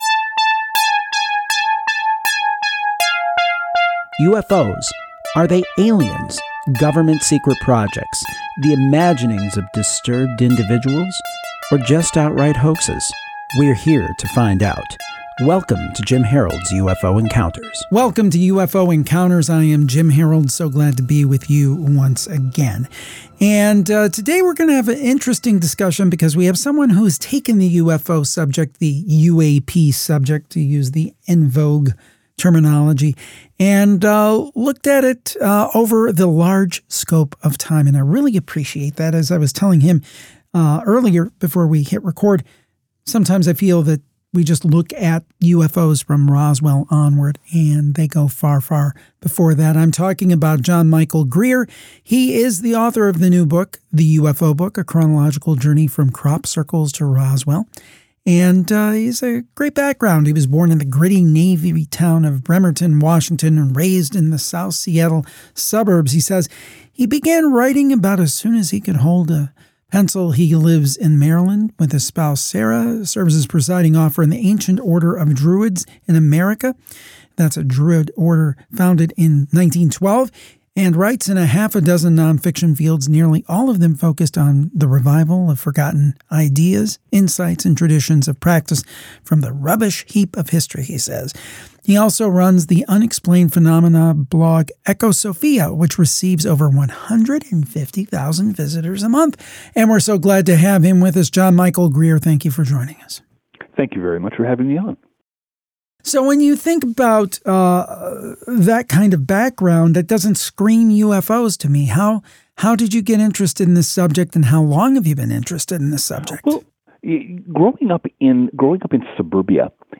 All three were good lively conversations, and the topics were about as different as I can manage!